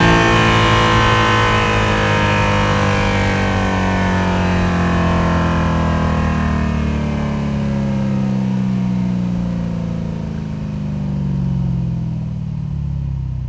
rr3-assets/files/.depot/audio/Vehicles/v8_10/v8_10_Decel.wav
v8_10_Decel.wav